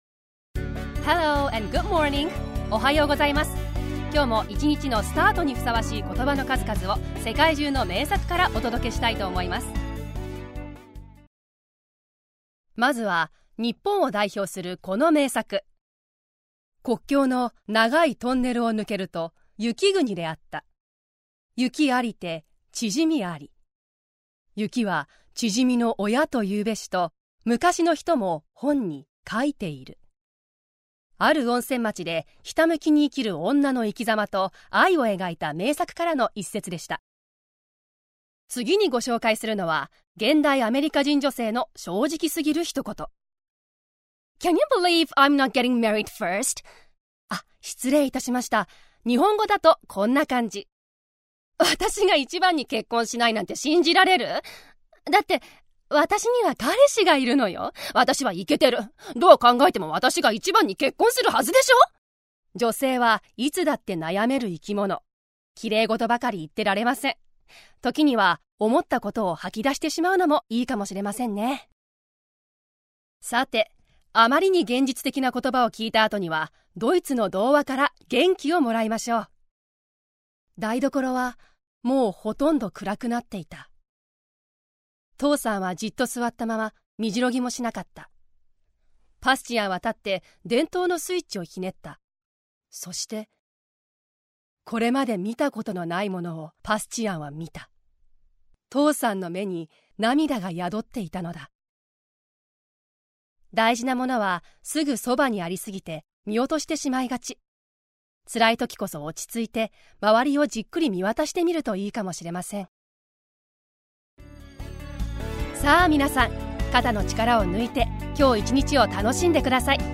ボイスサンプル
ナレーション
クールな女刑事
明るい女の子
気弱な女の子
まっすぐな少年